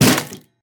Minecraft Version Minecraft Version 1.21.5 Latest Release | Latest Snapshot 1.21.5 / assets / minecraft / sounds / mob / irongolem / damage1.ogg Compare With Compare With Latest Release | Latest Snapshot
damage1.ogg